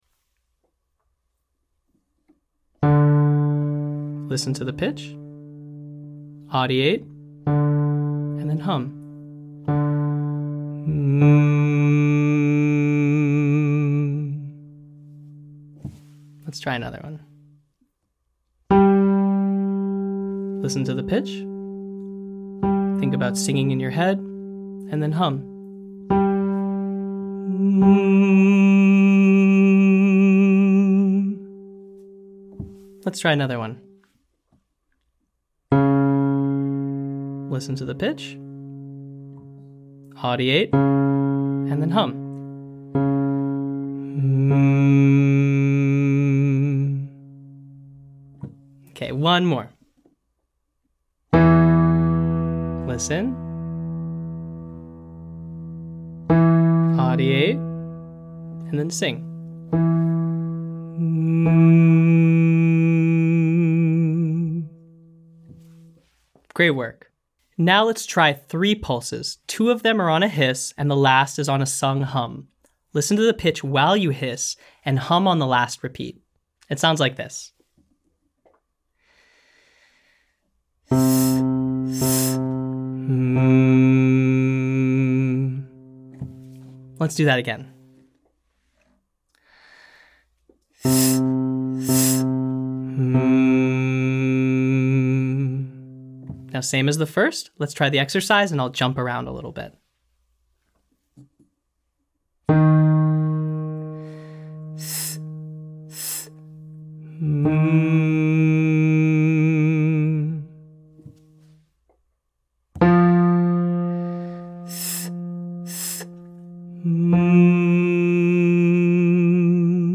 Warmup - Online Singing Lesson
Exercise: Listen, audiate, hum (single pitch)
Exercise: Hiss, hiss, hum (listen to pitch while you hiss, hum on the third)
Now, 3 pulses: 2 on a hiss, the last on a hum.
Exercise: Low high low siren (imprecise pitch)